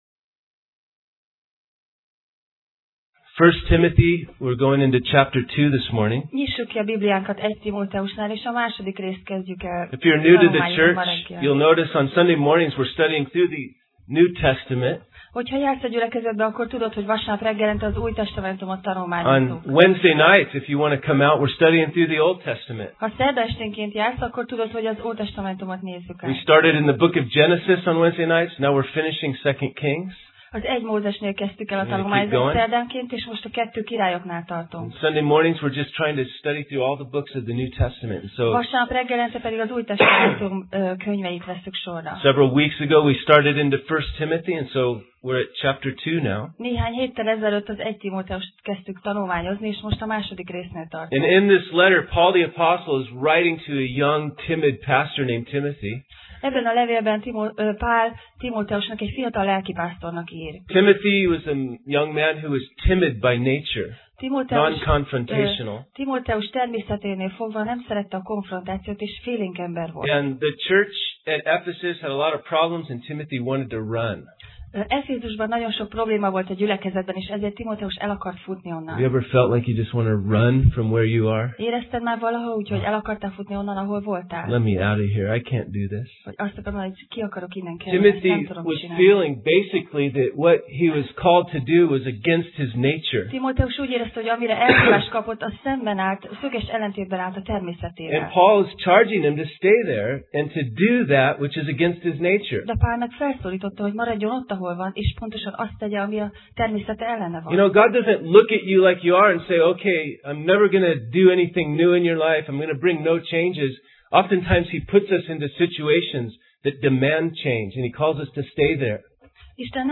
Passage: 1Timóteus (1Timothy) 2:1-6 Alkalom: Vasárnap Reggel